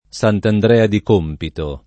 Sant’Andrea [Sant andr$a] top. — es.: Sant’Andrea di Compito [